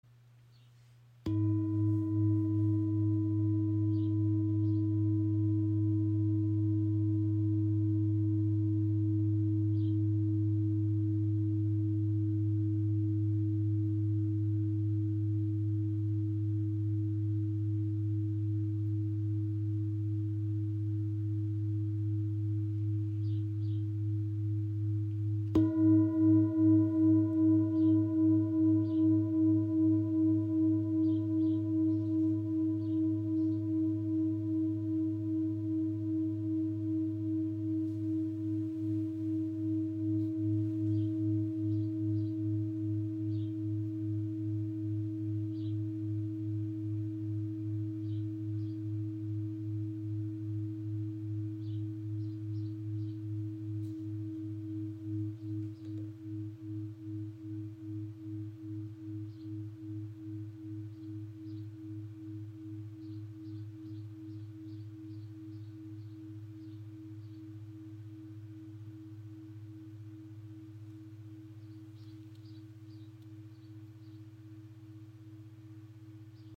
Tibetische Klangschale 31 cm – klarer Ton A# aus Nepal • Raven Spirit
Klarer, tiefer Ton A# – ideal für Klangarbeit, Meditation und Entspannung.
Klangbeispiel
Diese handgefertigte Klangschale aus Nepal trägt im Inneren die grüner Tara und aussen die acht Glückssymbole – zwei Symbole für Harmonie, Klarheit und innere Stärke. Ihr obertonreicher Klang im Ton A# ist klar und erdend.